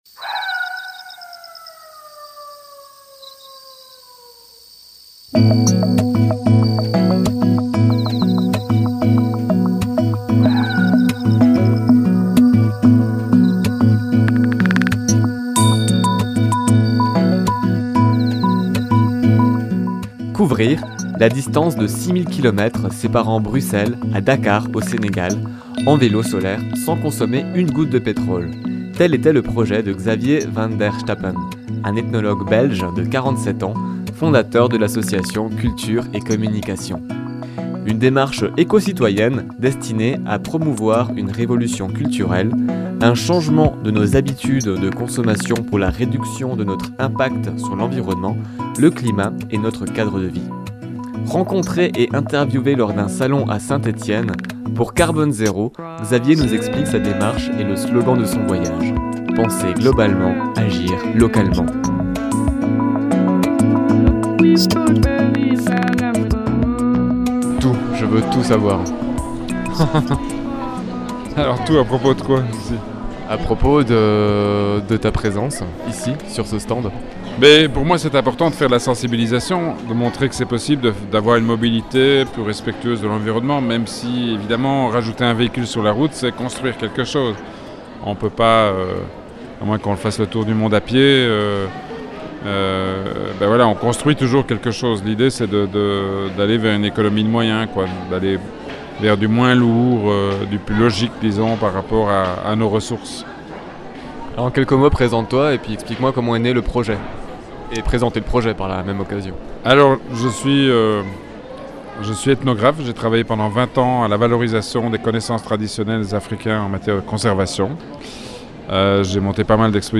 Rencontré et interviewé lors d'un salon à St Etienne